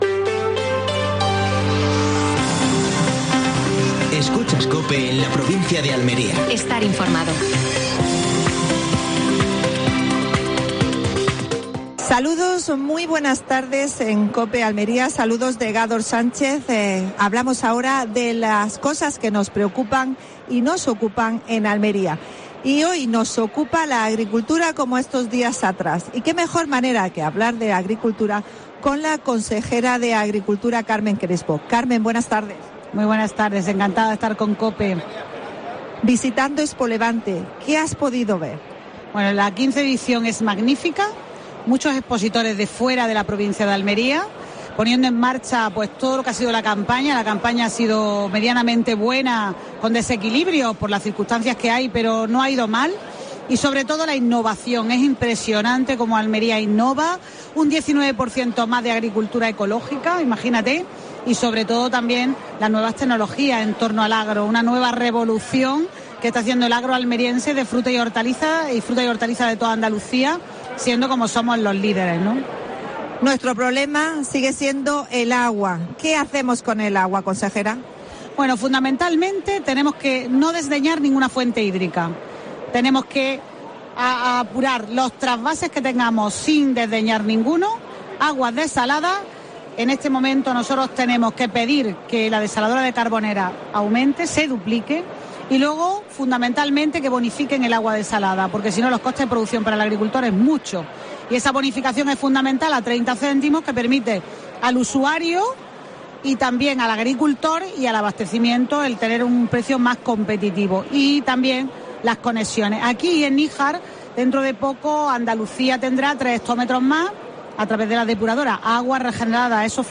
AUDIO: Última hora en Almería. Tercera jornada de ExpoLevante. Entrevista a Carmen Crespo (consejera de Agricultura de la Junta de Andalucía).